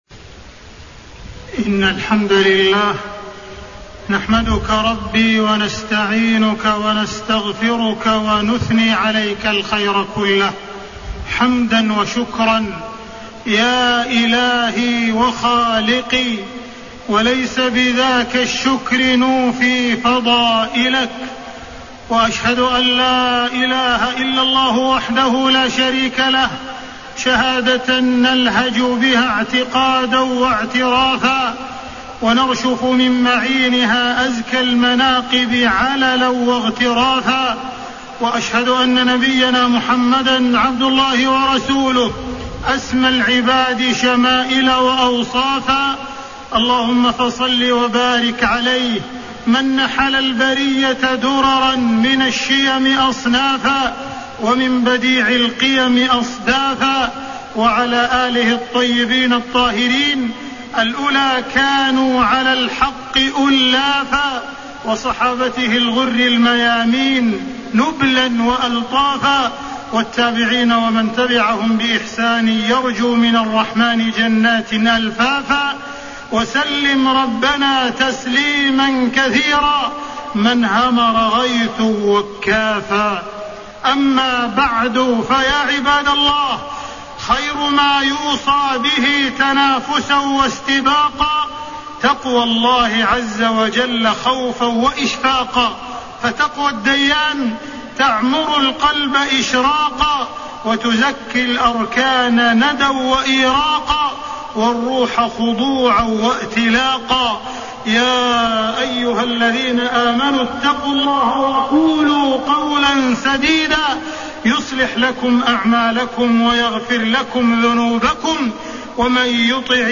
تاريخ النشر ٢٥ ربيع الأول ١٤٣٣ هـ المكان: المسجد الحرام الشيخ: معالي الشيخ أ.د. عبدالرحمن بن عبدالعزيز السديس معالي الشيخ أ.د. عبدالرحمن بن عبدالعزيز السديس غياب القيم في ظل الفتن